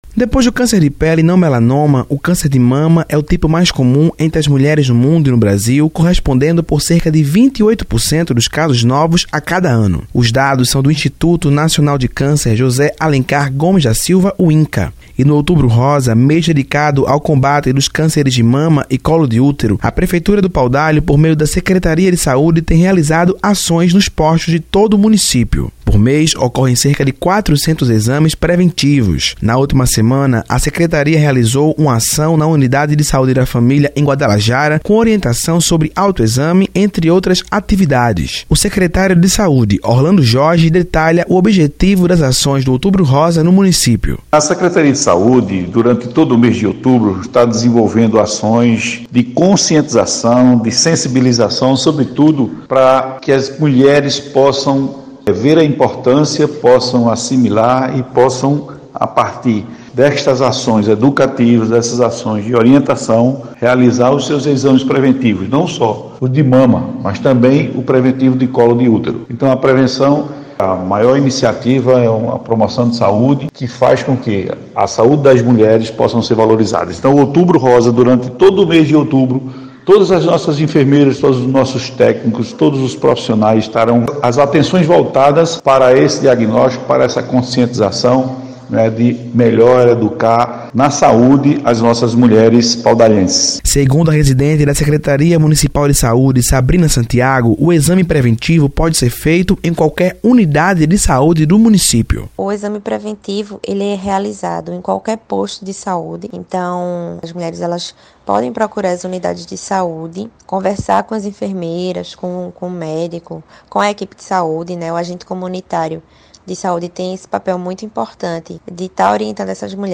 MATÉRIA-ESPECIAL-OUTUBRO-ROSA-P.18.10-online-audio-converter.com_.mp3